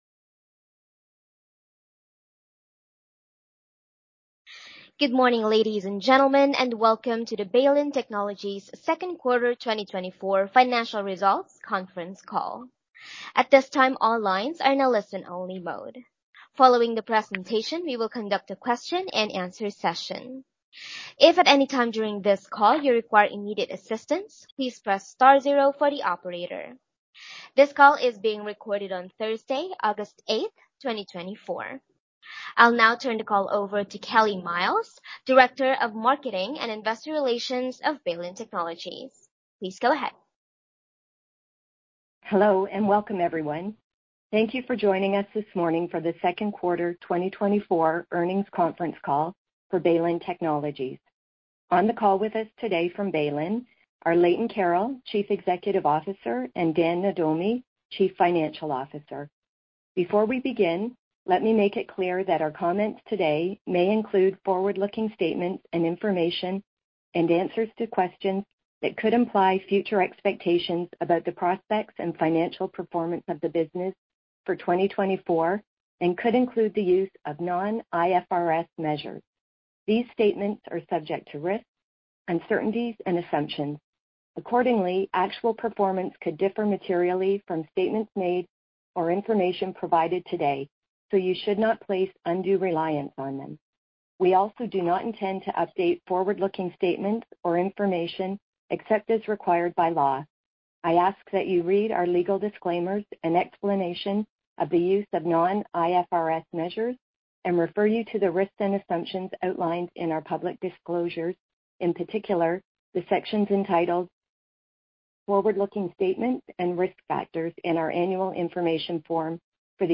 Baylin Technologies Inc. – Second Quarter 2024 Financial Results Conference Call